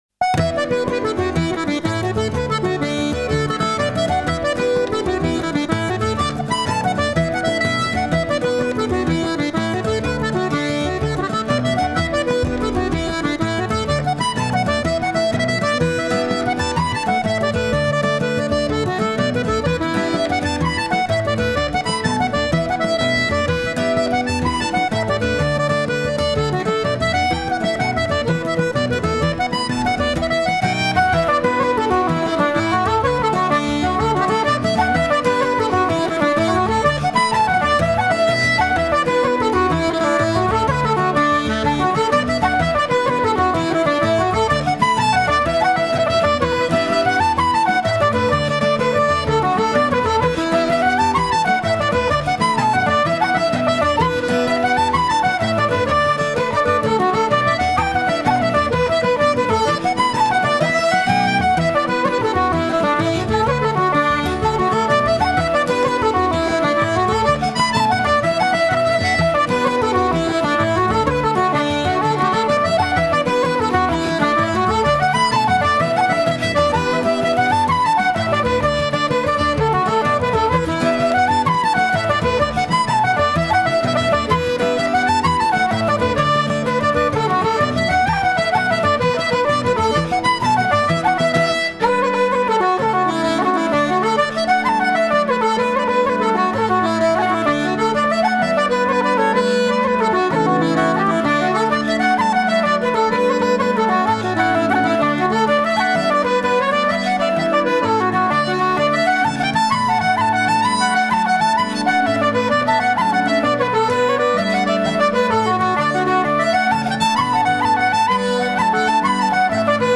I also learned some jigs.